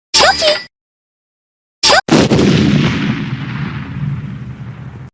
Play, download and share Shope explode original sound button!!!!
shope-explode.mp3